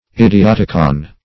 Search Result for " idioticon" : The Collaborative International Dictionary of English v.0.48: Idioticon \Id`i*ot"i*con\, n. [NL., fr. Gr.